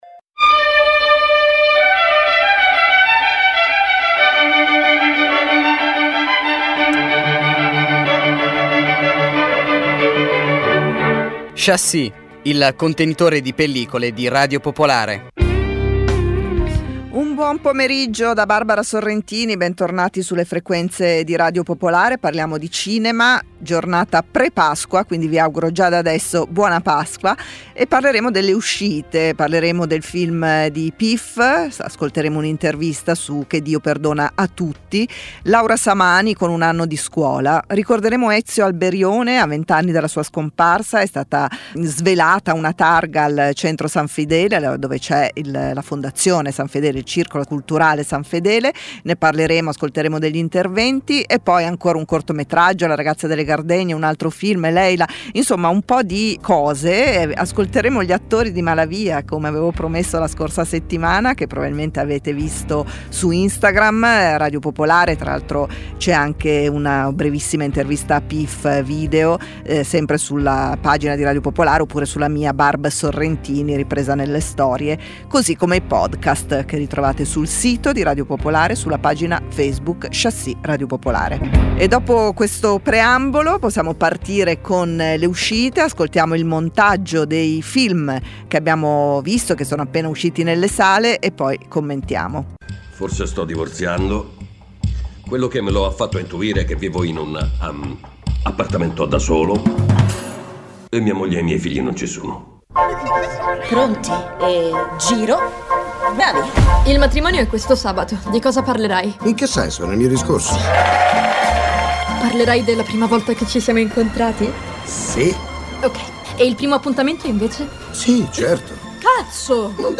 Ogni sabato offre un'ora di interviste con registi, attori, autori, e critici, alternando parole e musica per evocare emozioni e riflessioni cinematografiche. Include notizie sulle uscite settimanali, cronache dai festival e novità editoriali. La puntata si conclude con una canzone tratta da colonne sonore.